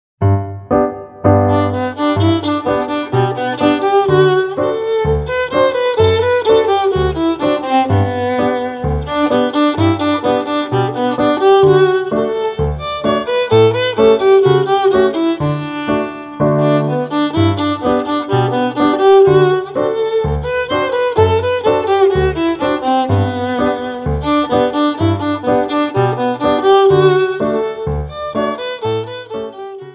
Besetzung: Viola
25 - Gavotte